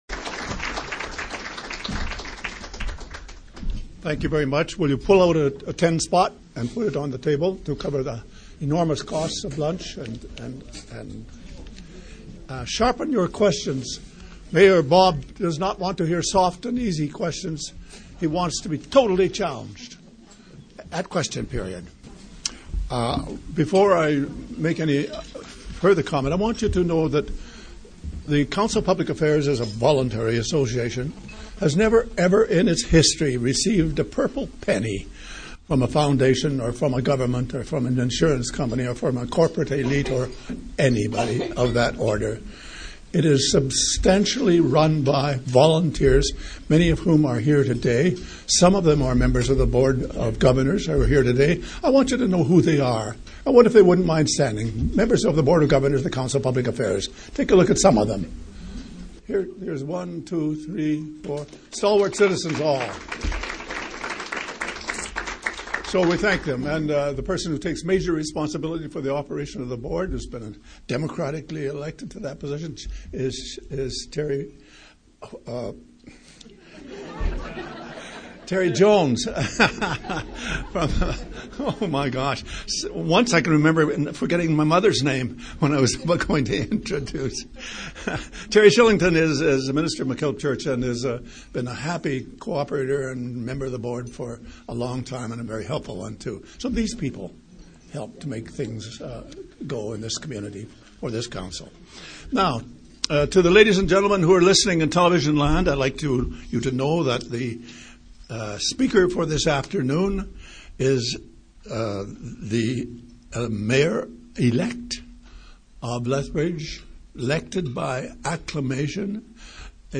Speaker: Mayor Bob Tarleck
Location: Ericksen’s Family Restaurant (lower level of The Keg)